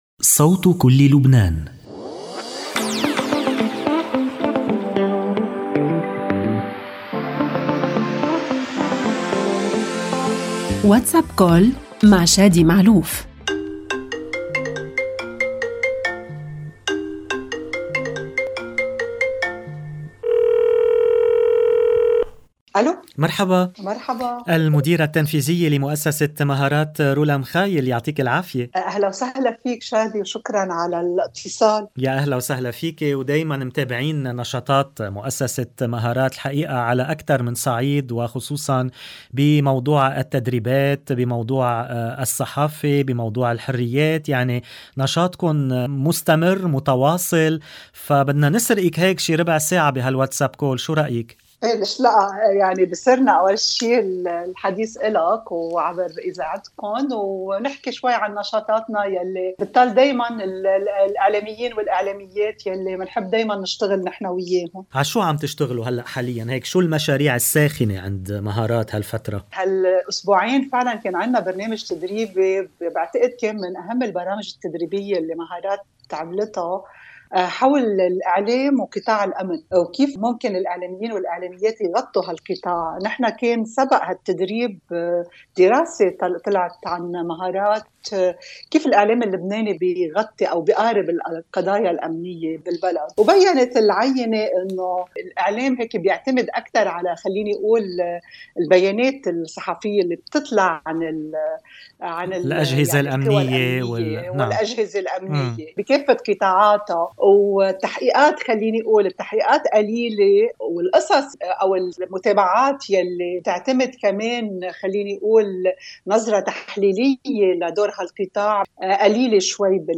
WhatsApp Call